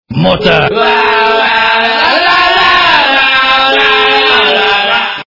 - рок, металл